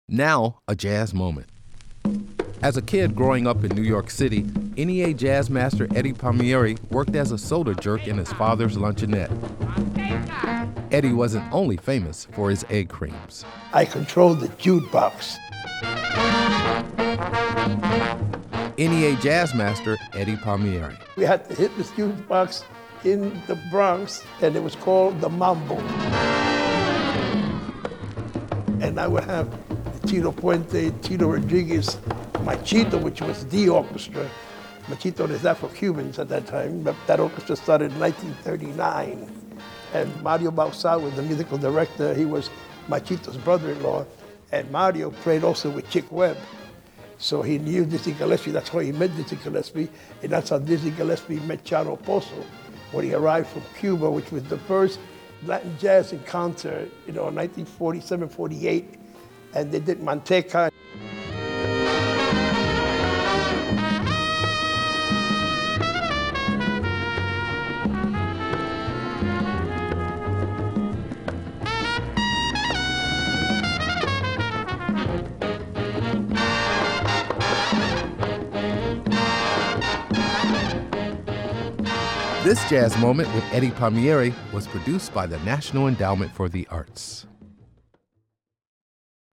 Excerpt of "Manteca" composed by Dizzy Gillespie and Chano Pozo and performed by Dizzy Gillespie and His Orchestra, used courtesy of Sony Music Entertainment and by permission of Music Sales Corp and Modern Works Publishing. (ASCAP)